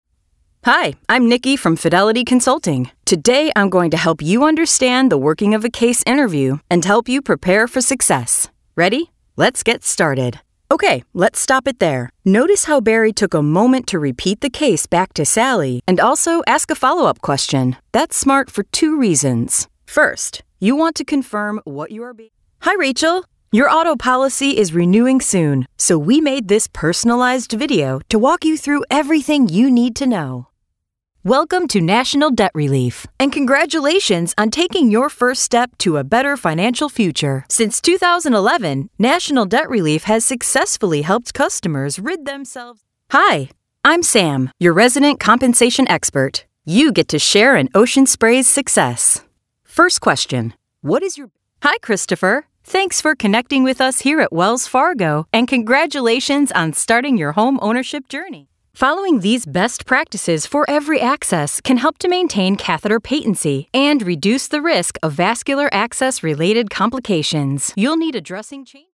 Vídeos explicativos
Micrófono MKH 415, Pro Tools, estudio insonorizado con funciones de masterización.